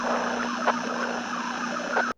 nerfs_psynoise3.ogg